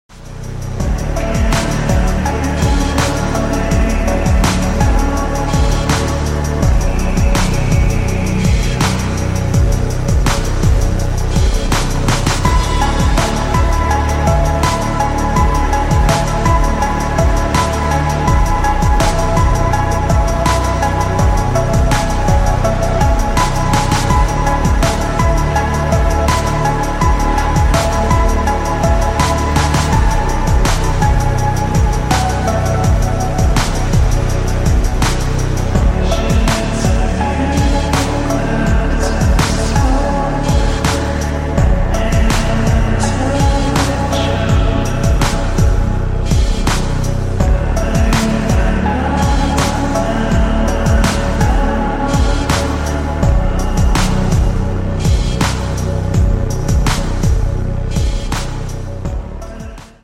(sʟᴏᴡᴇᴅ)